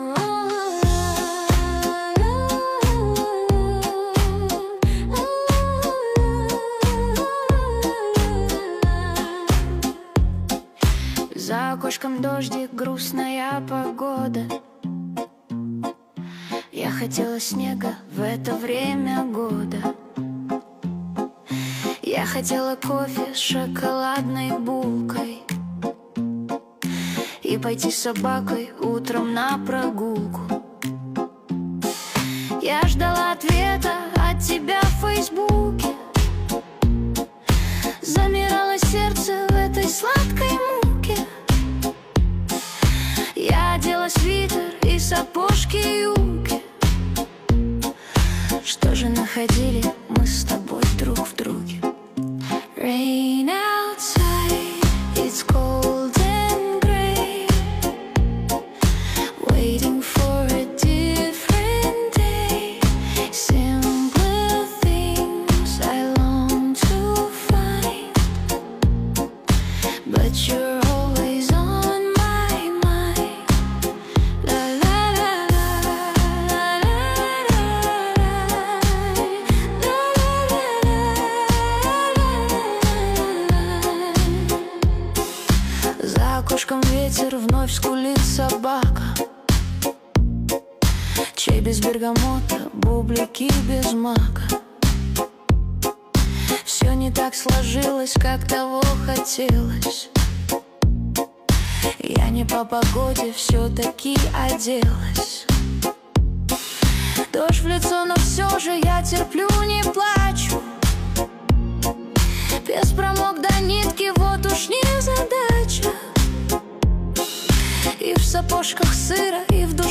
Качество: 320 kbps, stereo
Нейросеть Песни 2025, Стихи